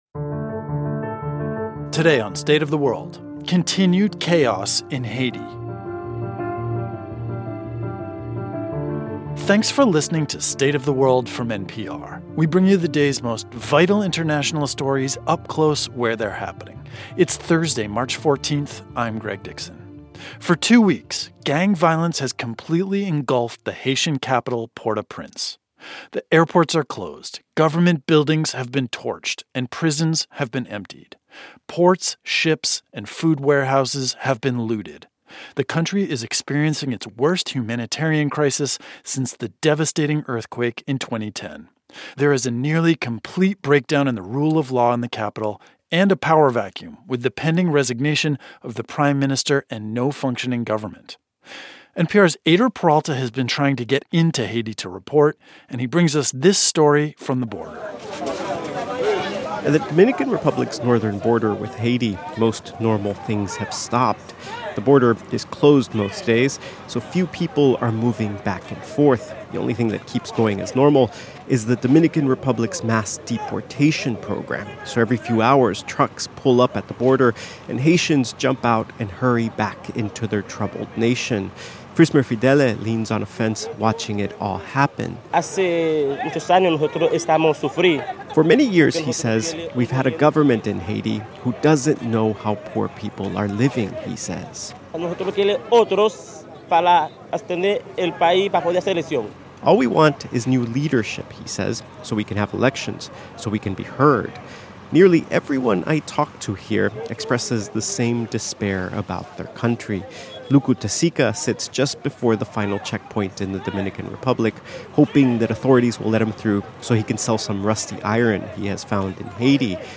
Our reporter on the border with the Dominican Republic talks to Haitians.